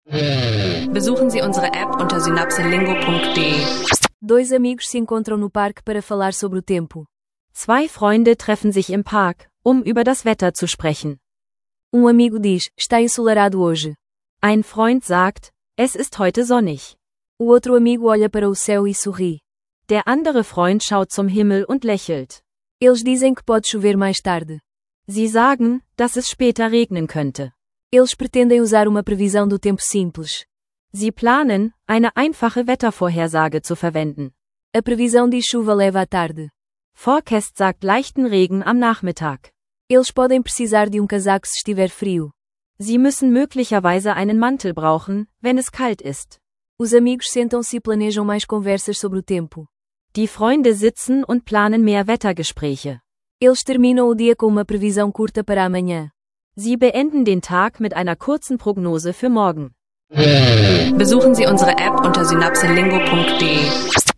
Wettergespräche leicht gemacht: Zwei Freunde üben einfache Sätze auf Portugiesisch und Deutsch.